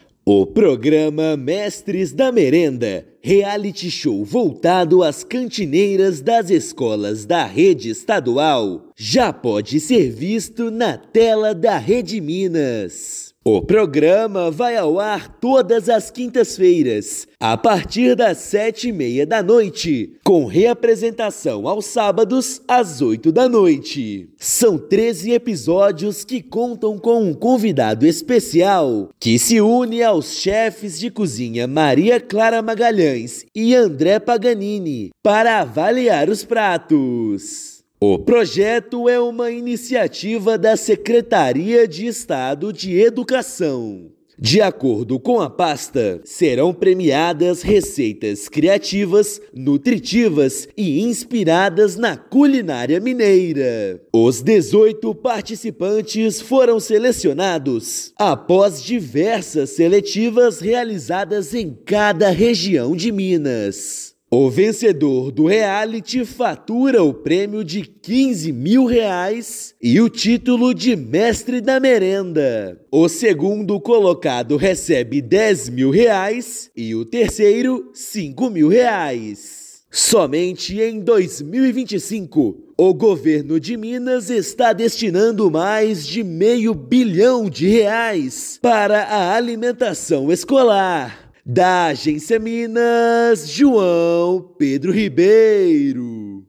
Reality show busca reconhecer e prestigiar profissionais que são o coração da cozinha das escolas estaduais. Ouça matéria de rádio.